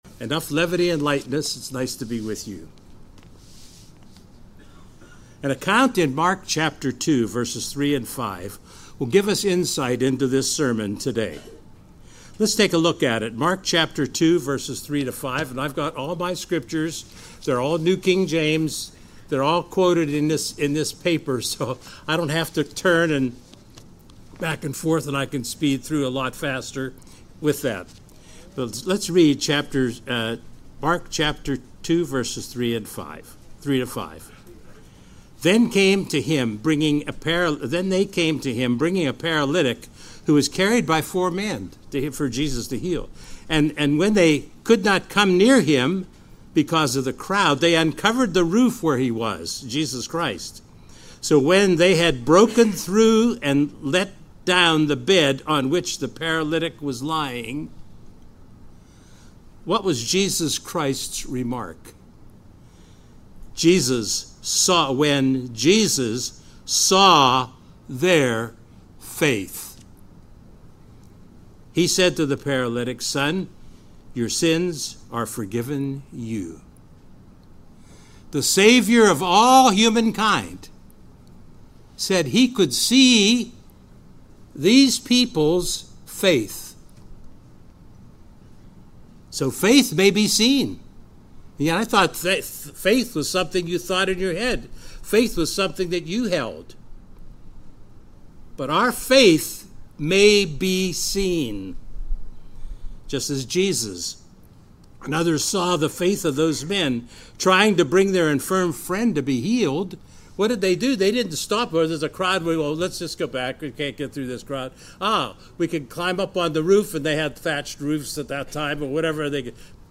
In this sermon we will see what faith is and how our faith may be seen by God and others.